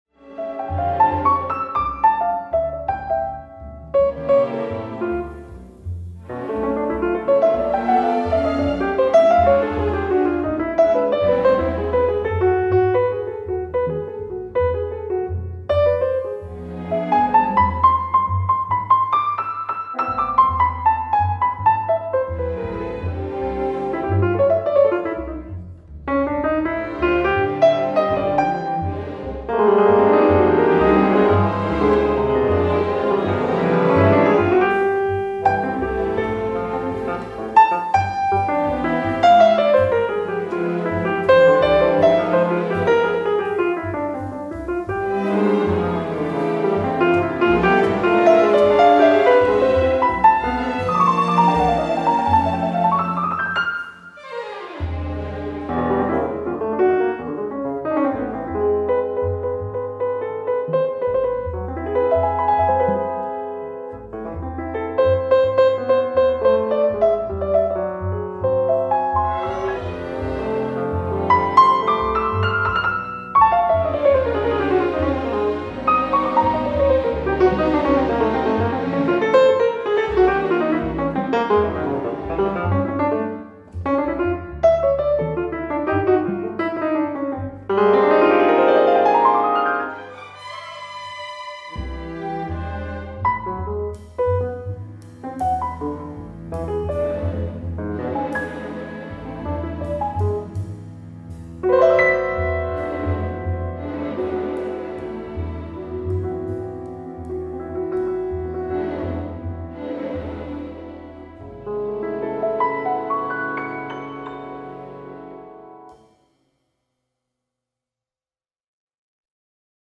Piano with strings